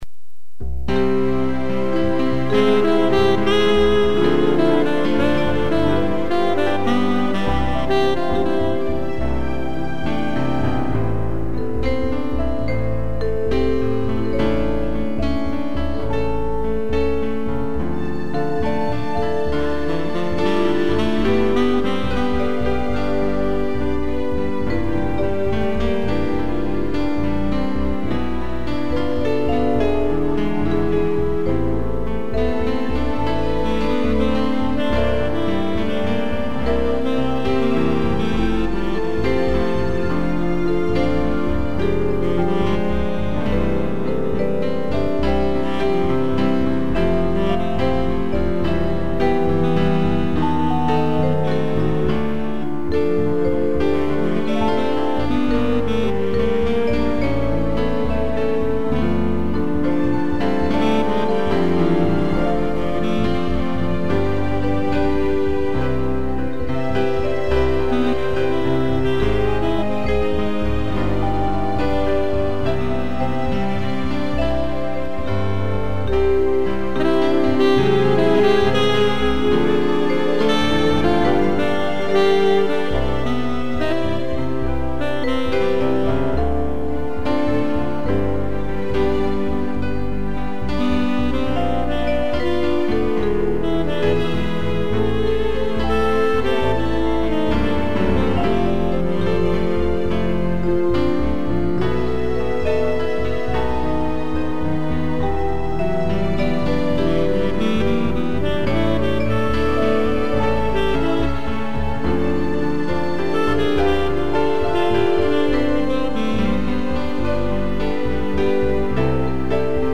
vibraforne, piano, sax e strings
(instrumental)